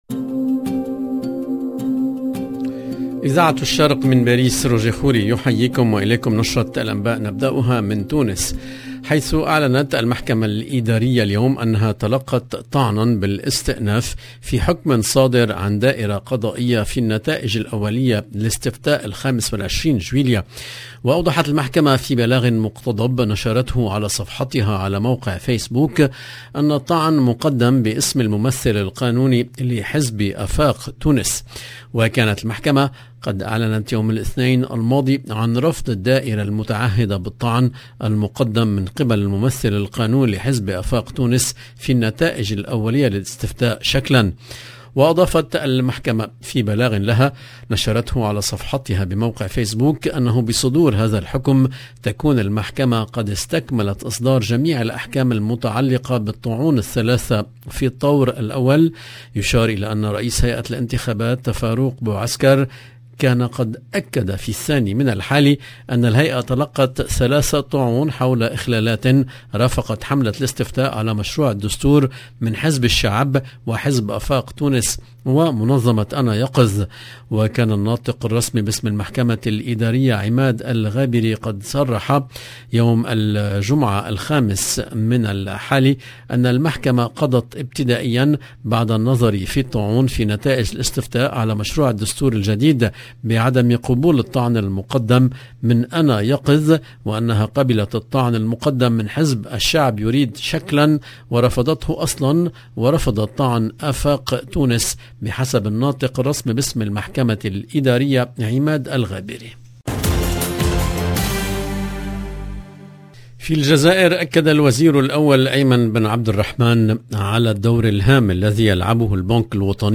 LE JOURNAL EN LANGUE ARABE DU SOIR 12/08/22